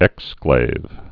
(ĕksklāv)